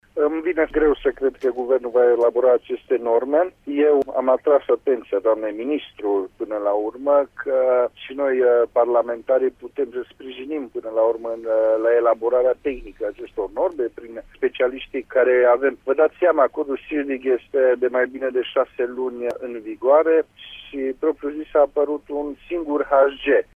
Deputatul Cristian Chirteș spune, însă, că nu vede cum s-ar putea respecta un termen atât de strâns: